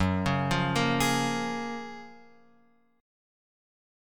F# Major 9th